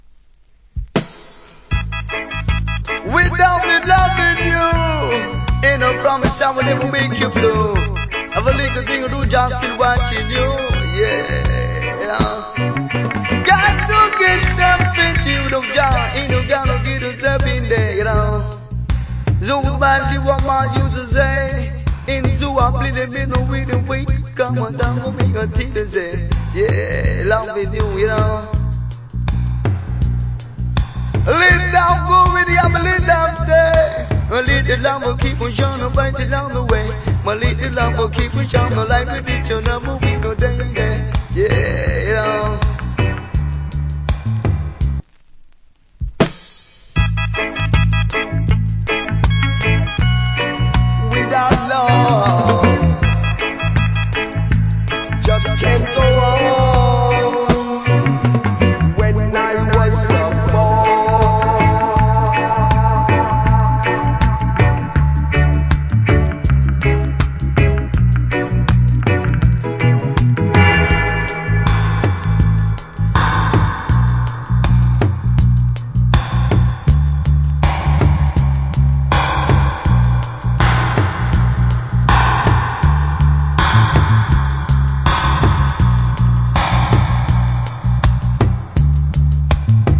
Wicked DJ.